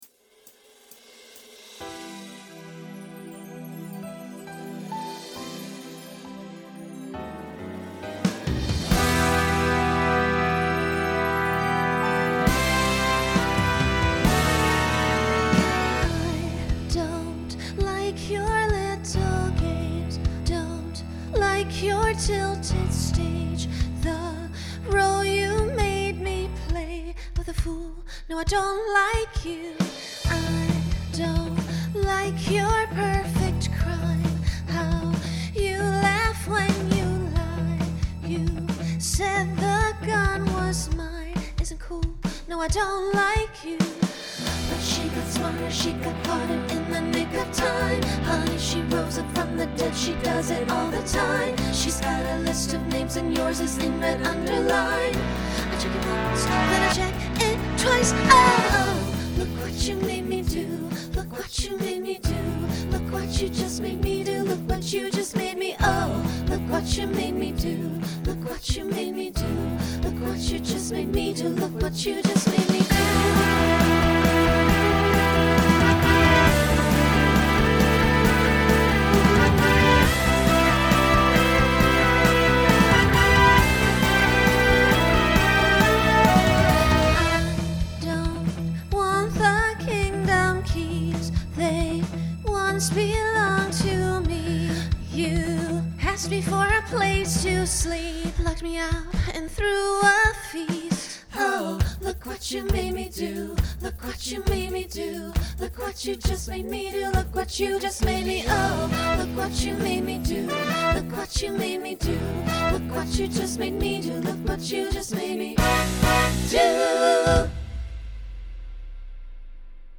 New SSA voicing for 2025.
Genre Pop/Dance Instrumental combo
Voicing SATB , SSA